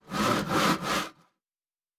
Wood 14.wav